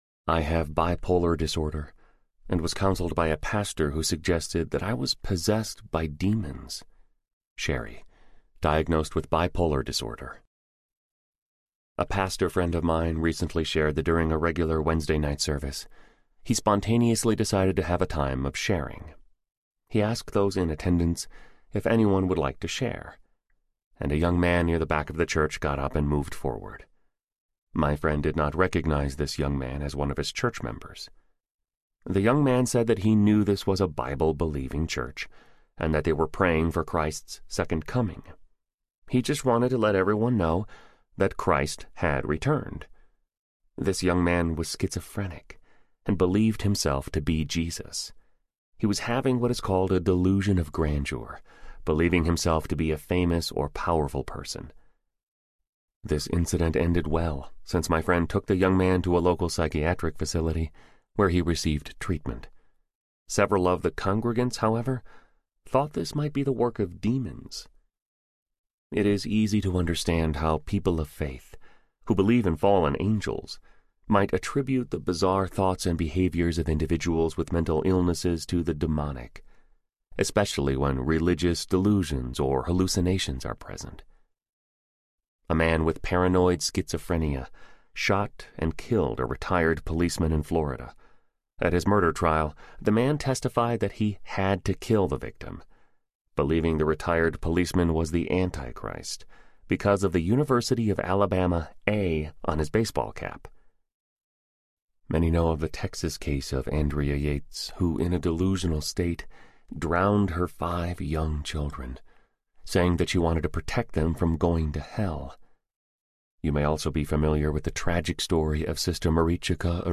Grace for the Afflicted Audiobook
Narrator
8.0 Hrs. – Unabridged